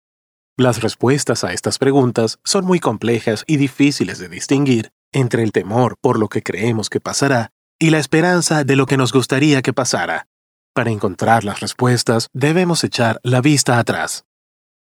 Latin American male voice overs